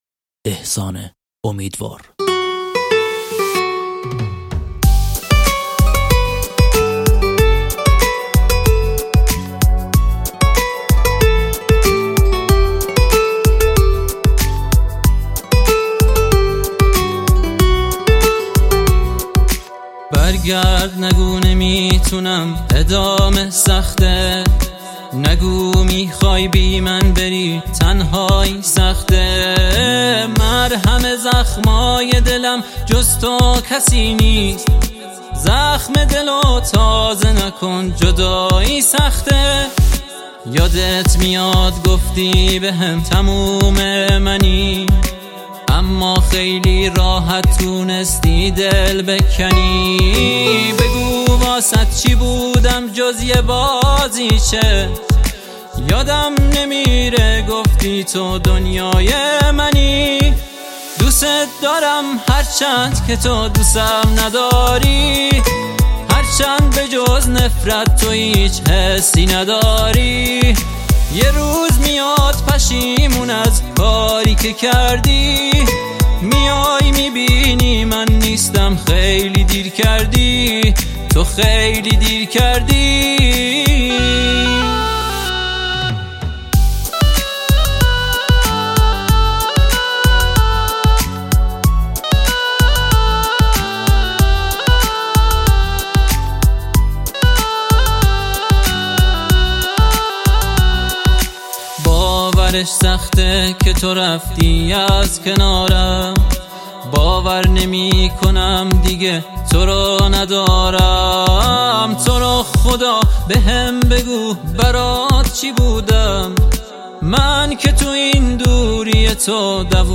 کردی